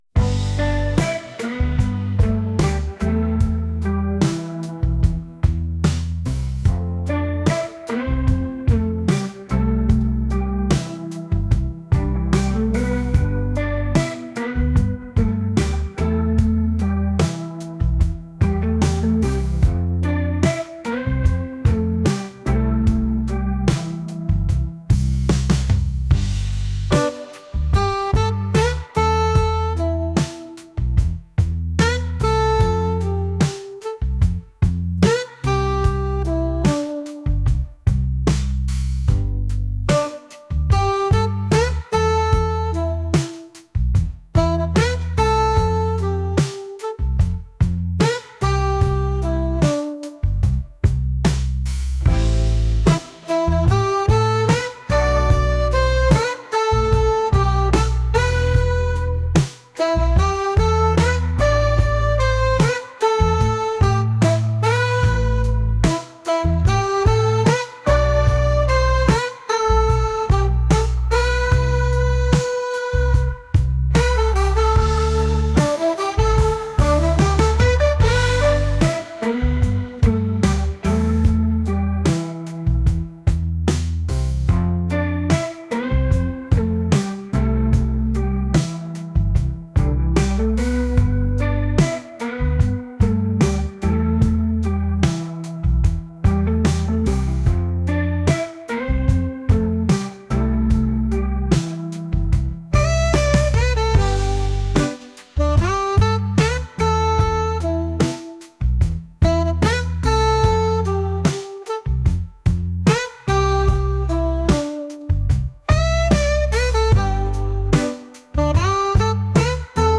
soulful | indie | acoustic